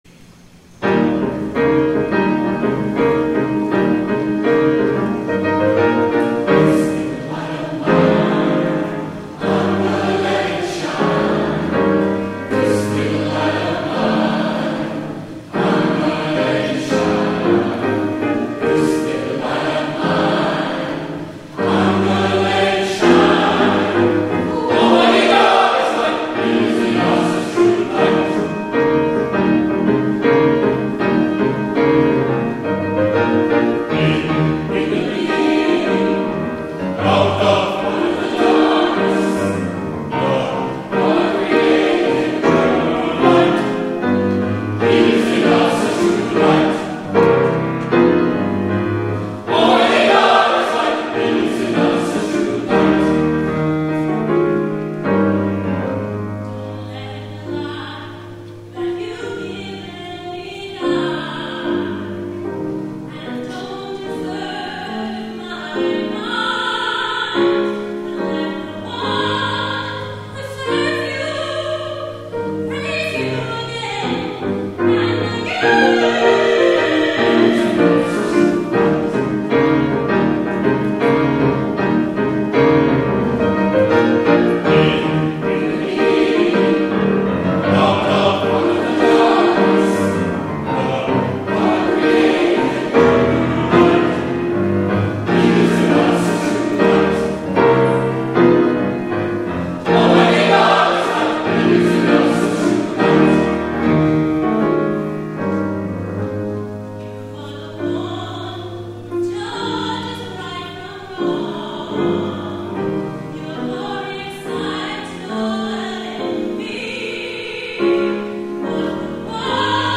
THE ANTHEM
soprano
piano